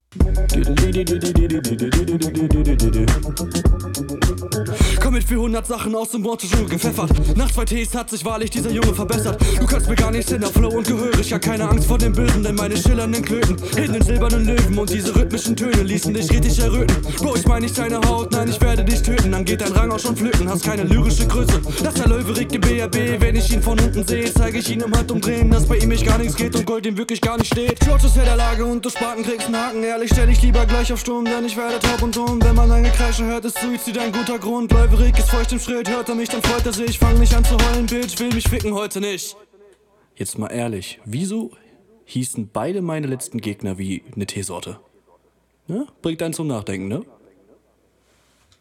Lustiger Beat, am Anfang ein paar Unsicherheiten, dann gar nicht mehr. Cool gerappt.
Cool geflowt und Reime kommen cool, auch wenn hart unnötig gezweckt.